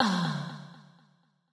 AIR Ah FX F.wav